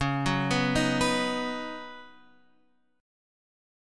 Db9sus4 chord